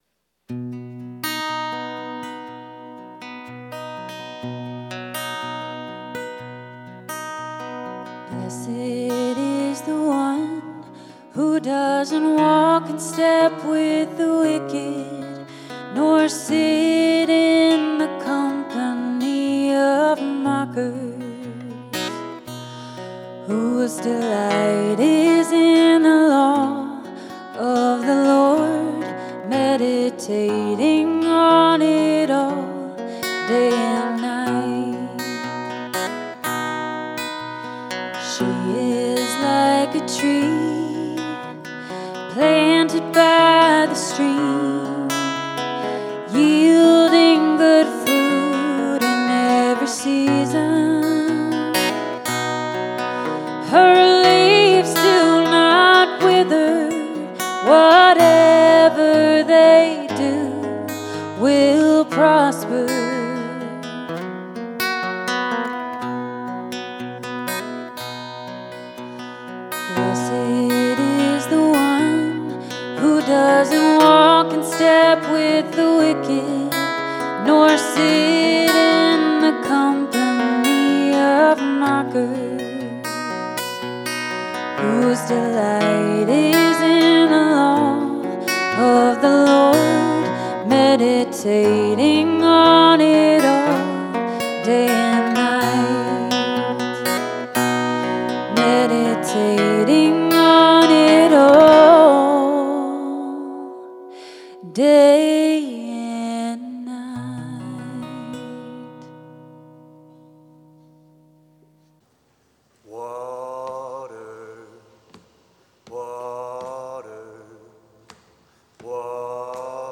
Each week, our musicians have set a psalm to music.